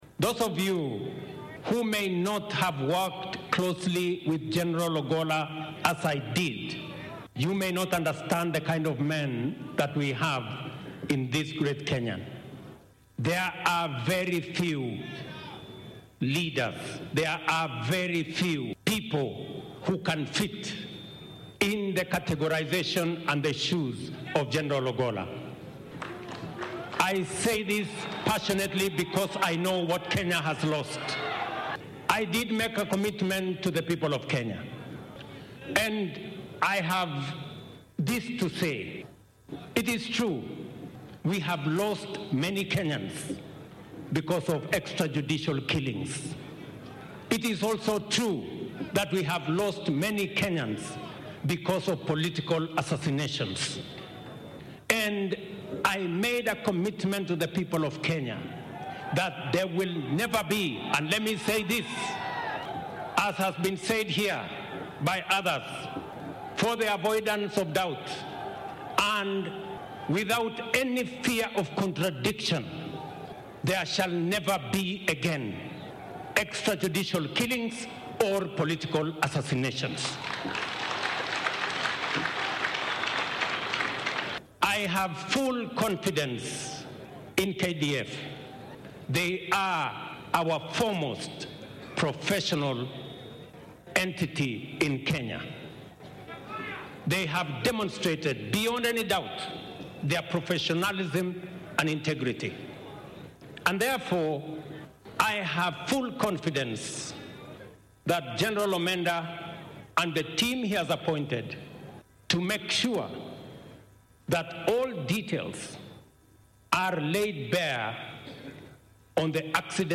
President William Ruto spoke Sunday at General Ogolla’s funeral at the conclusion of three days of national mourning